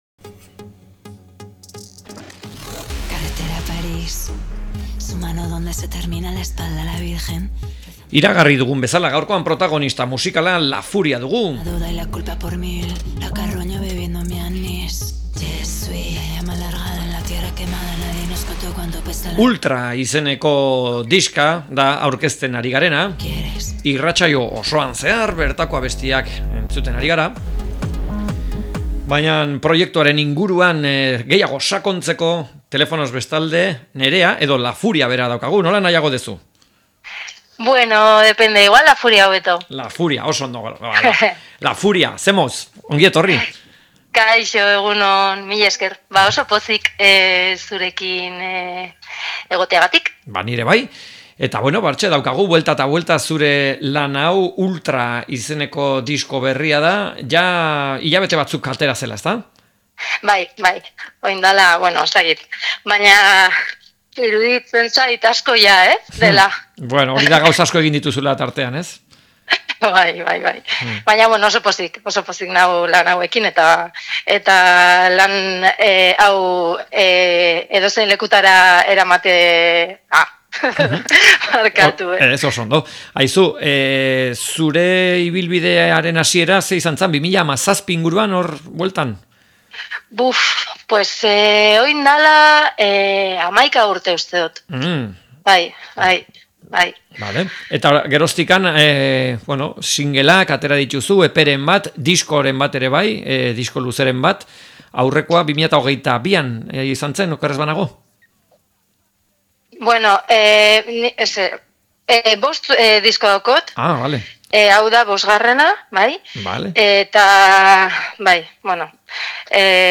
La Furia-ri elkarrizketa